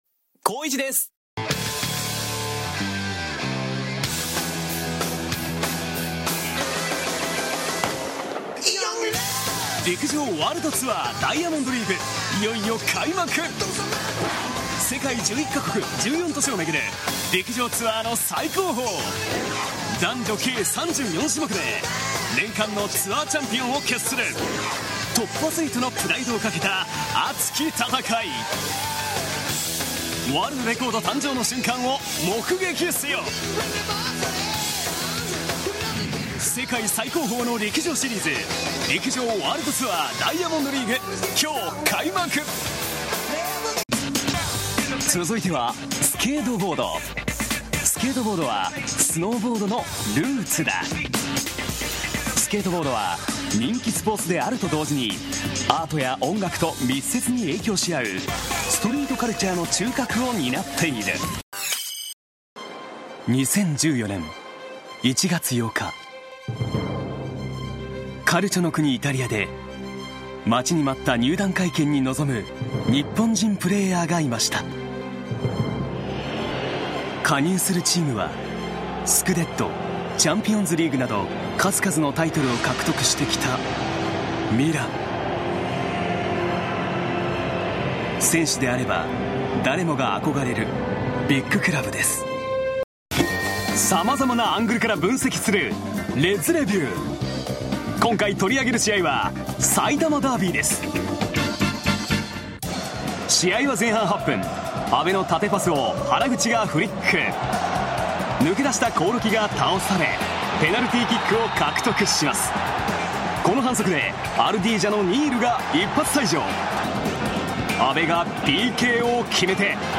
• 現在はフリーランスのナレーターとして、朝の情報番組など、全国番組で大活躍。
🍊スポーツ番組向けのボイスサンプル。